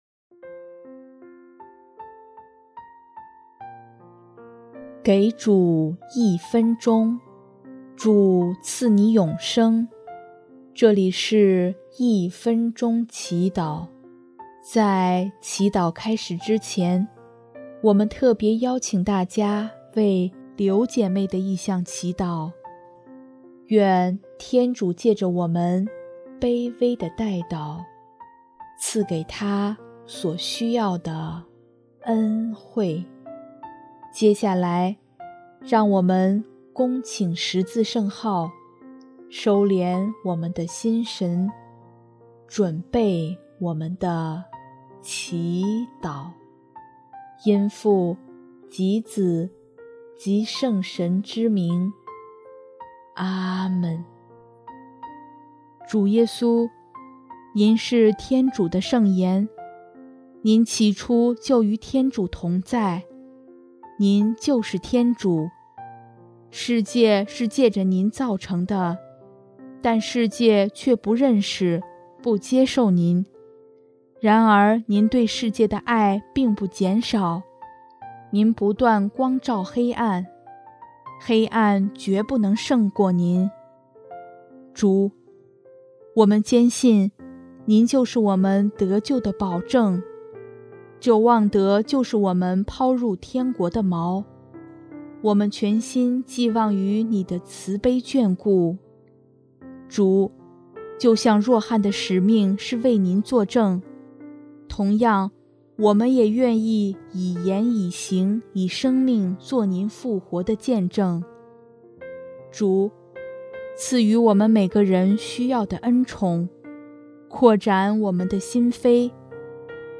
【一分钟祈祷】|12月31日 主，让我们成为您的证人